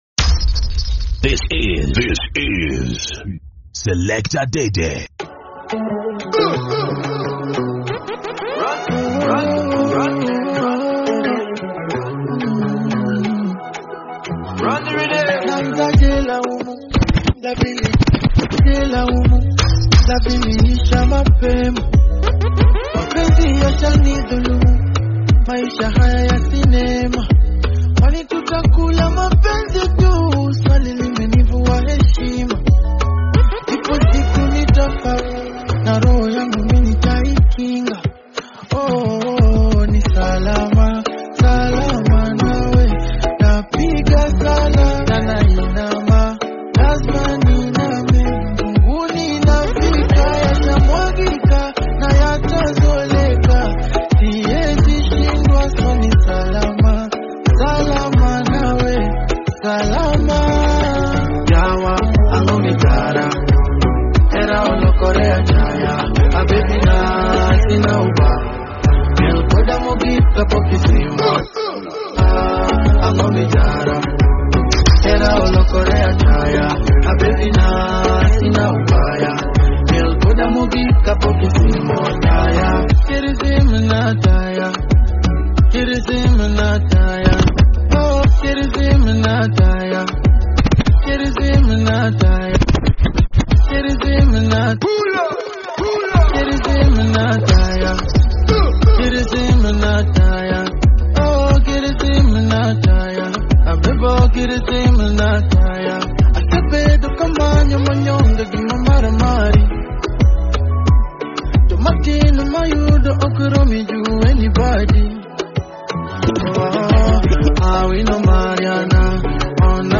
Afrobeat 1 plays 2 downloads 108 views --